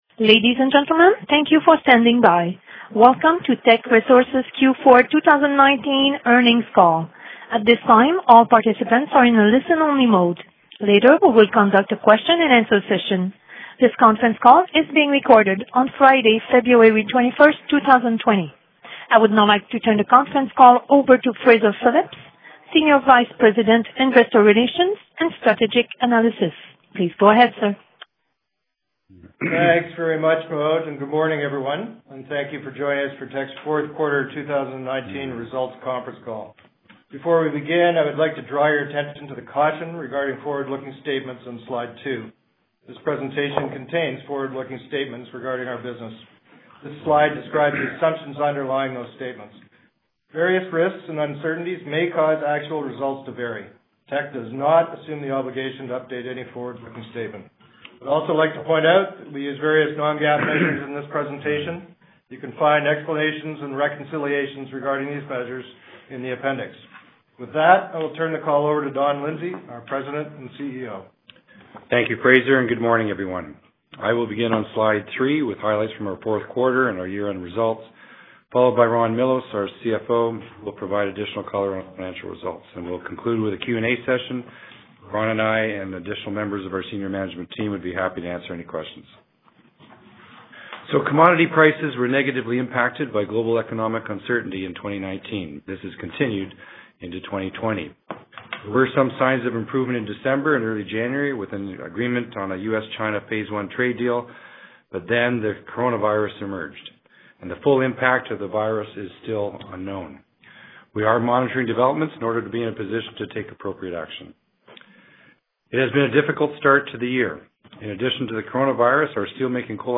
Q4-2019-Financial-Report-Conference-Call-Audio.mp3